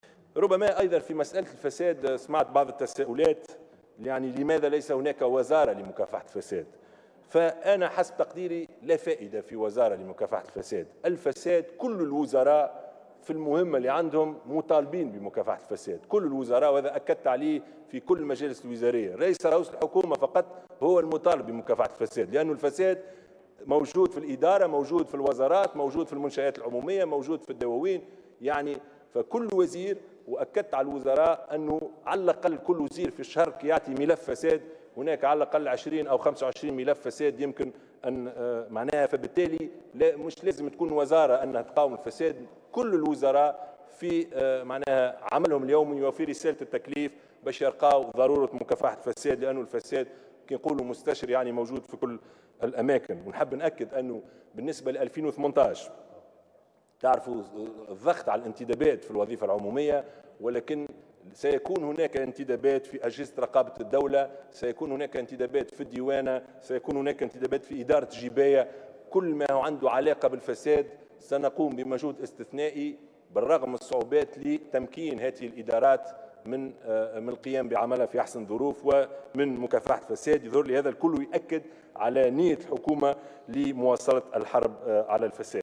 وأضاف الشاهد في مداخلة له خلال الجلسة العامة المخصصة لمنح الثقة لأعضاء الحكومة الجدد أنه سيتم خلال 2018 القيام بانتدابات استثنائية في أجهزة رقابة الدولة والديوانية وإدارة الجباية وكل ما له علاقة بمكافحة الفساد، على الرغم من صعوبة الظرف الاقتصادي وذلك لتمكين هذه الإدارات من القيام بعملها في أحسن الظروف.